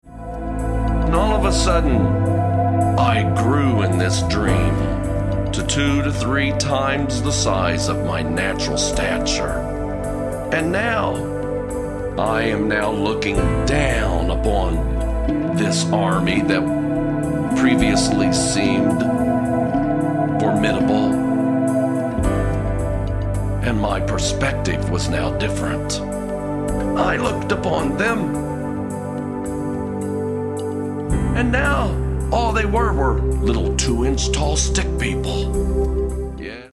Background rhythms with flowing melodies